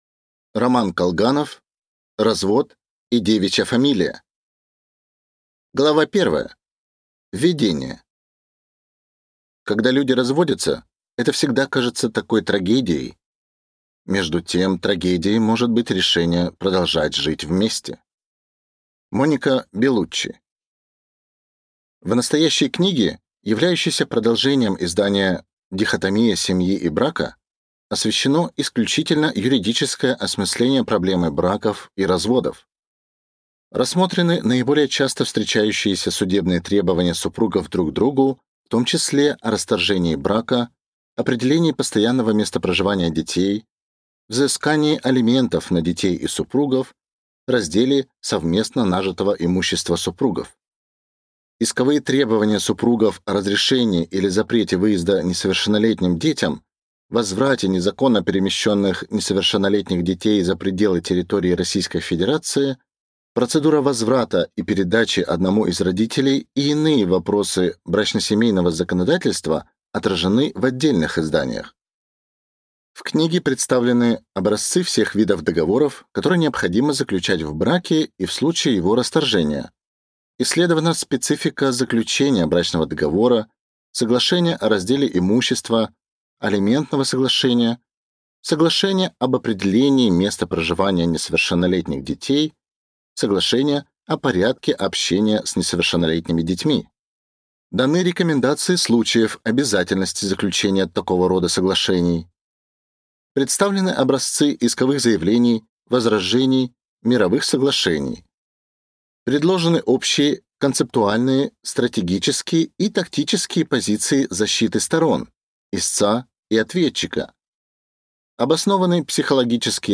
Аудиокнига Развод и девичья фамилия | Библиотека аудиокниг